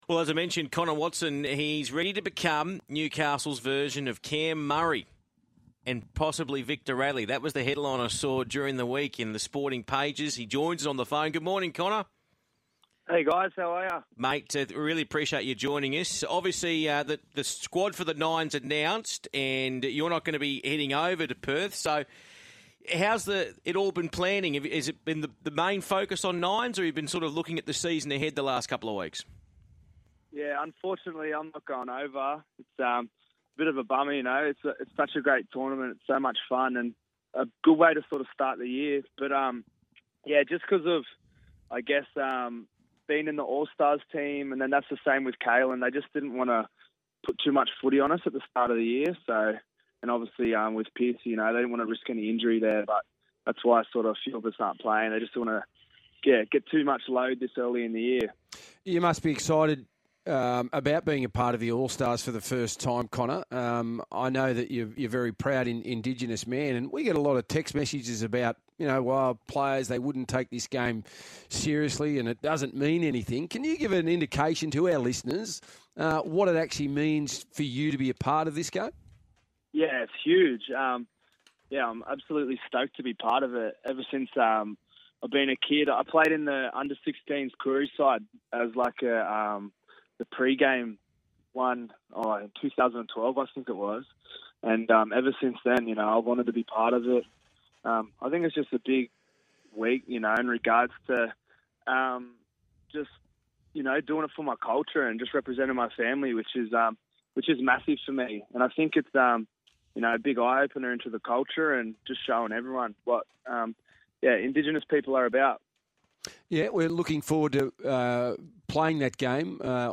Connor Watson speaks on the Sky Sports Radio's Big Sports Breakfast regarding Kalyn Ponga's fitness ahead of the 2020 NRL season for the Newcastle Knights.